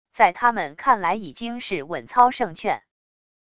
在他们看来已经是稳操胜券wav音频生成系统WAV Audio Player